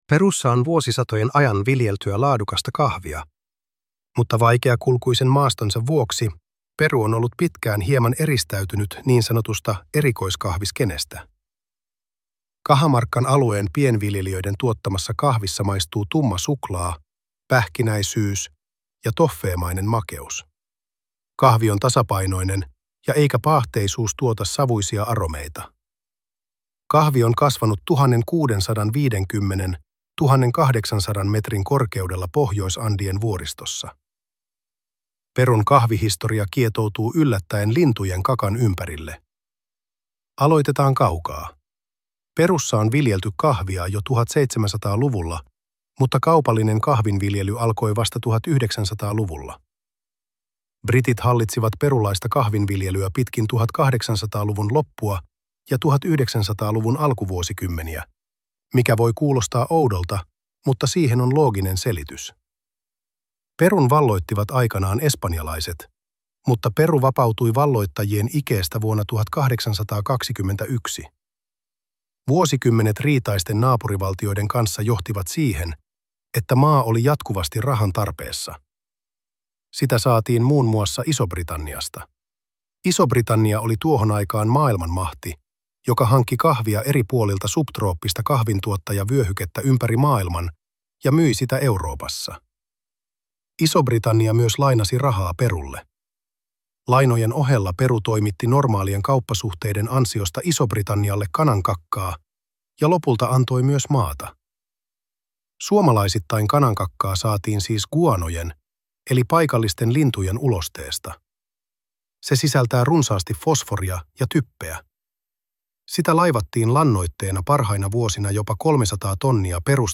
Tarinan äänitiedosto on luotu tekoälyllä.